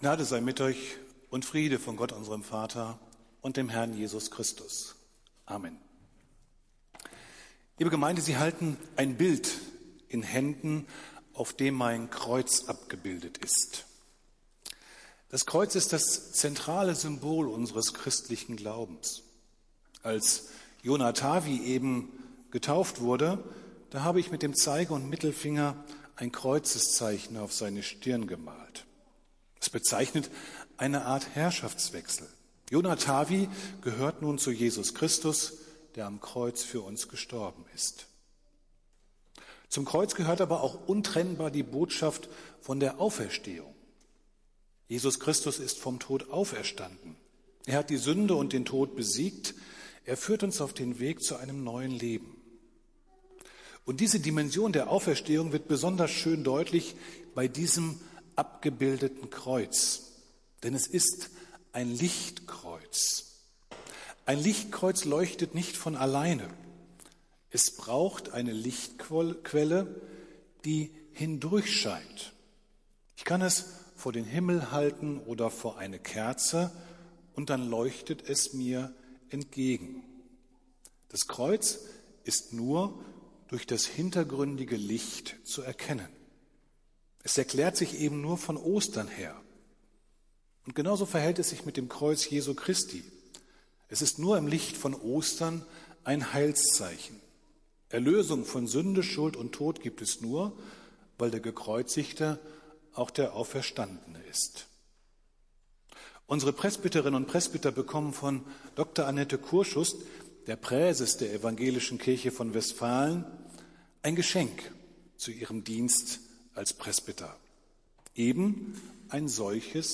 Predigt des Gottesdienstes aus der Zionskirche vom Sonntag, den 22.08.2021
Wir haben uns daher in Absprache mit der Zionskirche entschlossen, die Predigten zum Nachhören anzubieten.